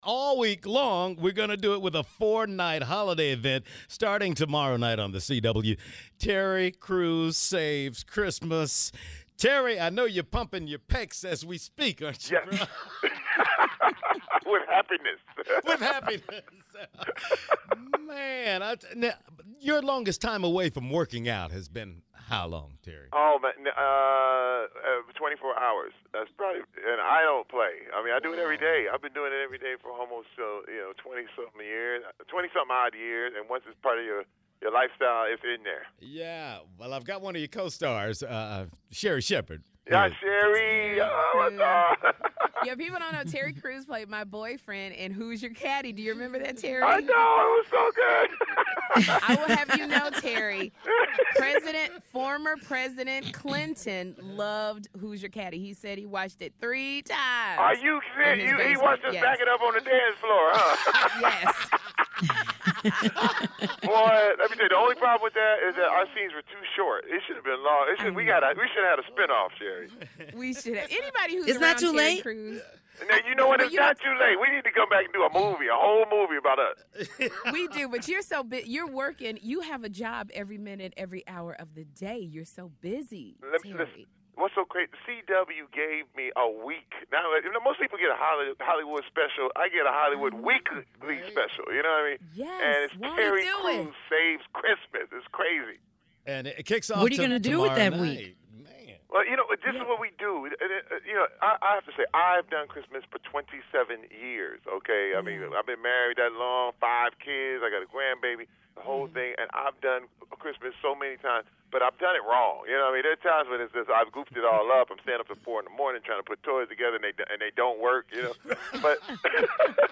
Listen to the interview below to hear all about his latest show, married life, maintaining a happy life, and much more below.